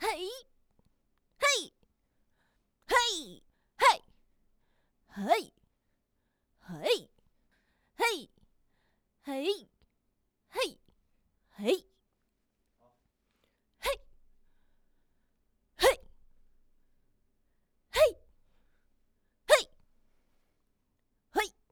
嘿.wav 0:00.00 0:21.72 嘿.wav WAV · 1.8 MB · 單聲道 (1ch) 下载文件 本站所有音效均采用 CC0 授权 ，可免费用于商业与个人项目，无需署名。
人声采集素材/女激励/嘿.wav